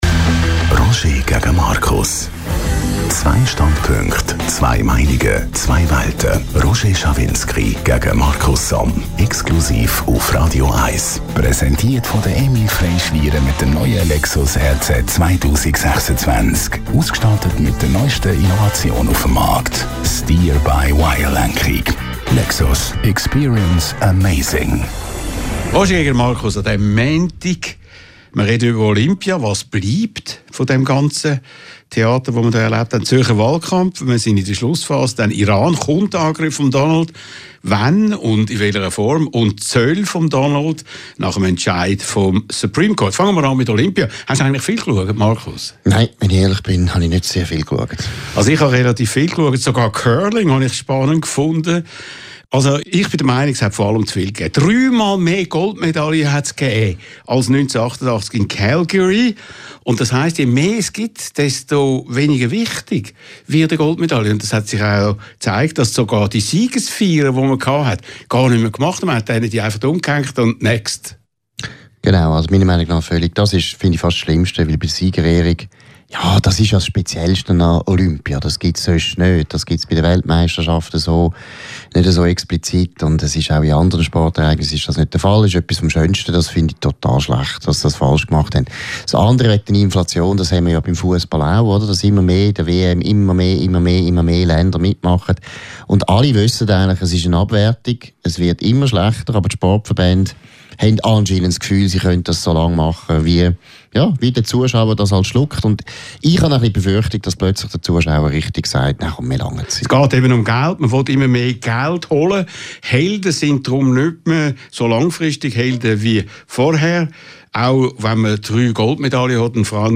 Publizist Markus Somm und Radio 1-Chef Roger Schawinski diskutieren in kontroverser Form über aktuelle Themen der Woche. Die Live-Sendung fand im Restaurant Dörfli in Uitikon statt.